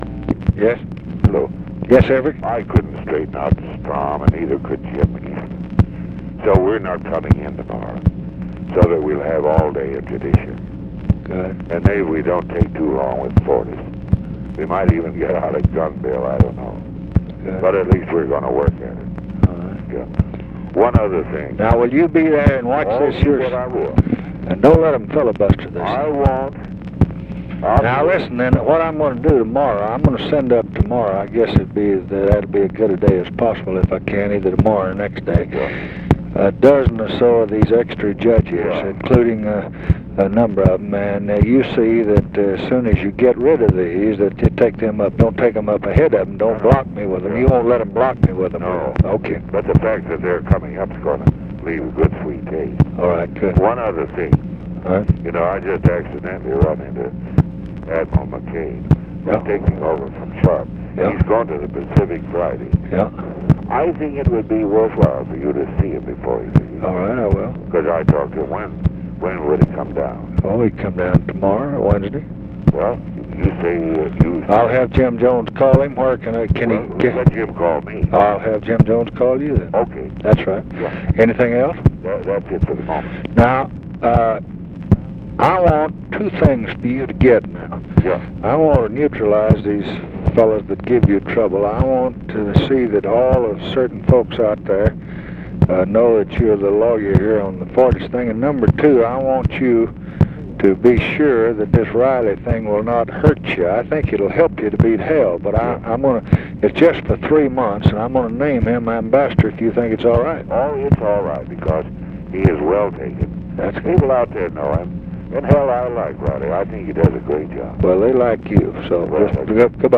Conversation with EVERETT DIRKSEN, July 15, 1968
Secret White House Tapes